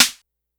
snr_09.wav